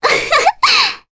Ophelia-Vox-Laugh_2_jp.wav